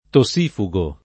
vai all'elenco alfabetico delle voci ingrandisci il carattere 100% rimpicciolisci il carattere stampa invia tramite posta elettronica codividi su Facebook tossifugo [ to SS& fu g o ] agg. e s. m. (med.); pl. m. -ghi